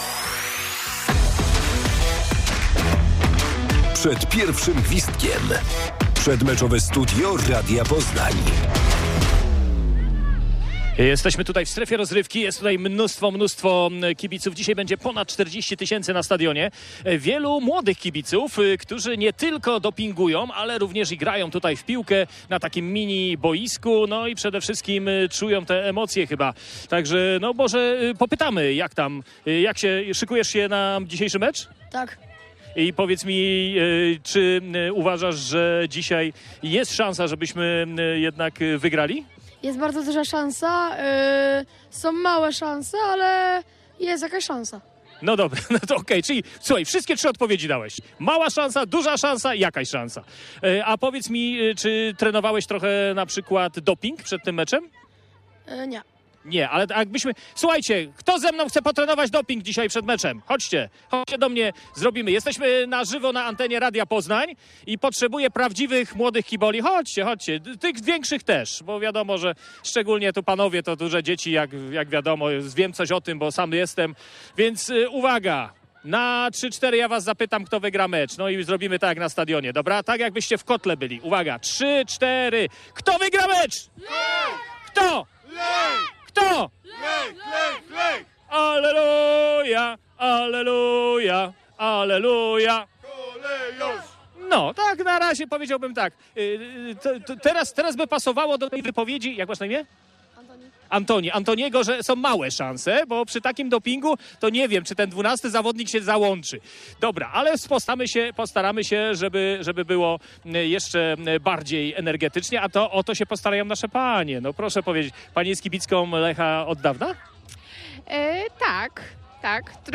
Radiowy namiot stanął tuż przed stadionem Lecha Poznań przy Bułgarskiej.
Tuż przed meczem rozmawialiśmy z fanami Kolejorza i ćwiczyliśmy doping.